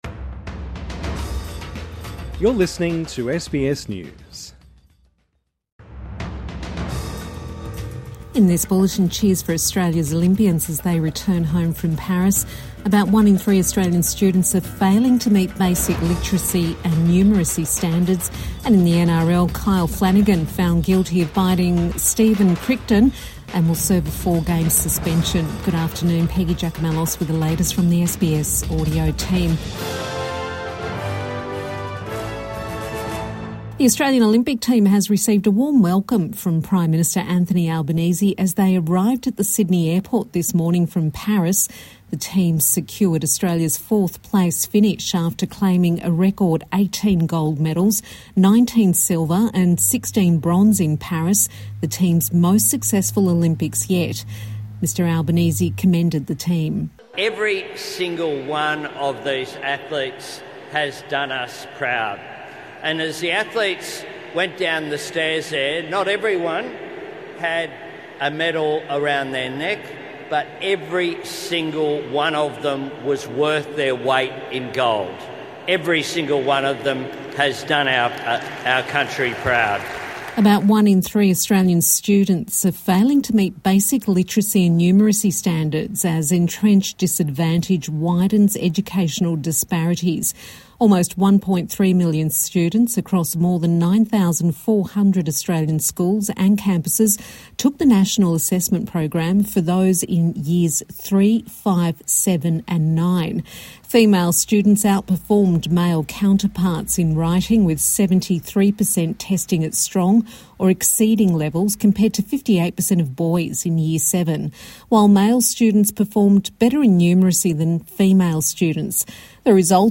Midday News Bulletin 14 August 2024